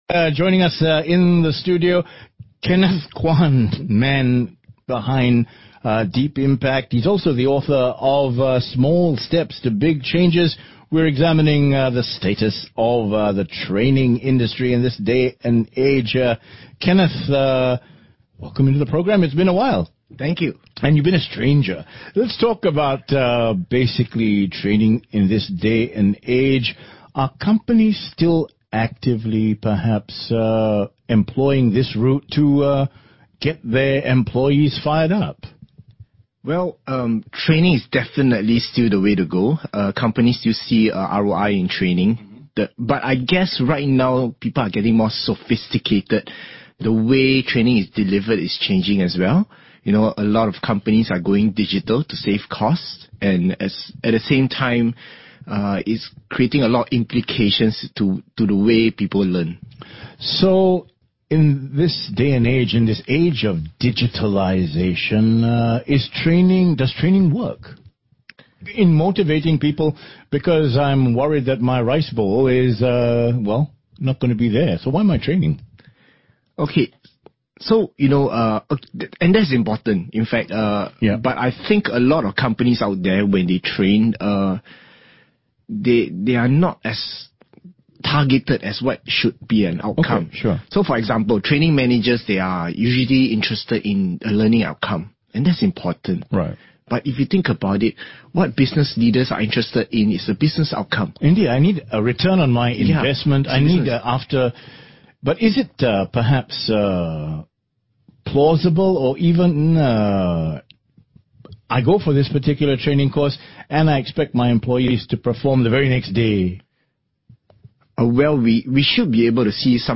938NOW Radio Interview: Is training still relevant in this digital age?
Transcript of the radio interview